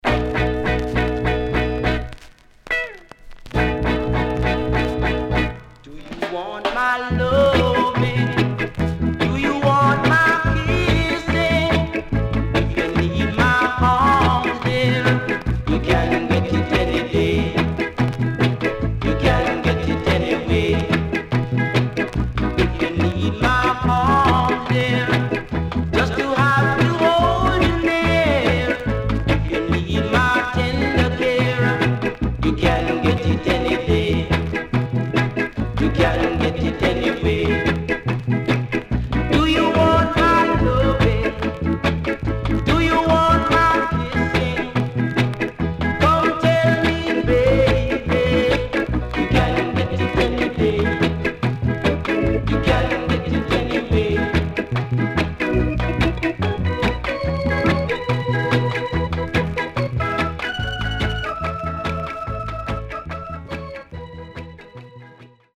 CONDITION SIDE A:VG(OK)〜VG+
Traditional Song Nice Early Reggae Inst Cover & Vocal
SIDE A:うすいこまかい傷ありますがノイズあまり目立ちません。